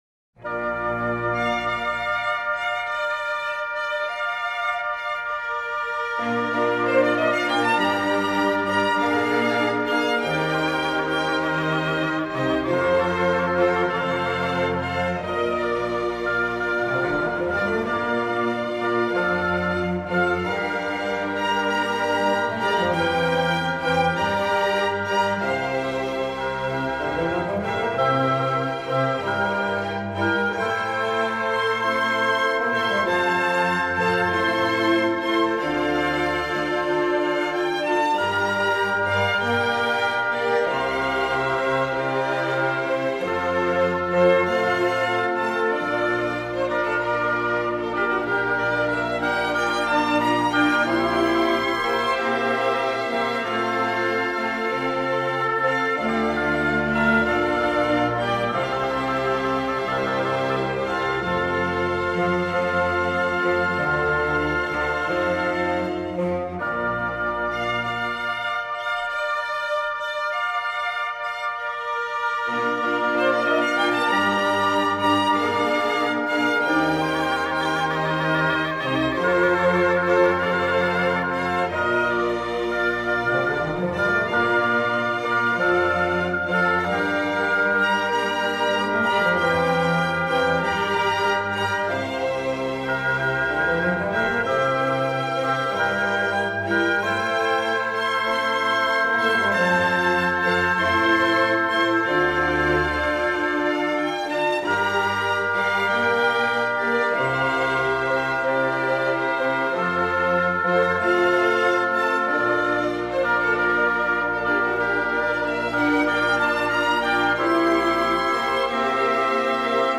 Chorus 1 / Violin solo from Aria, Verse 4 / Chorale